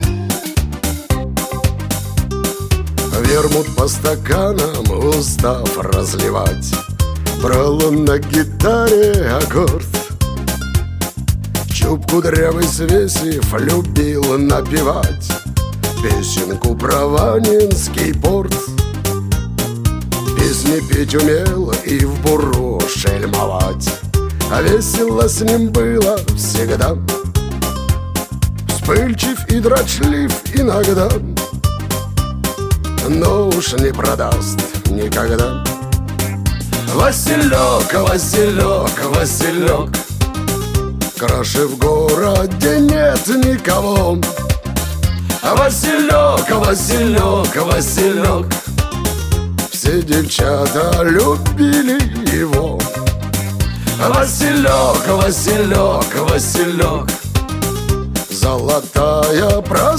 Шансон
мелодичным и хриплым голосом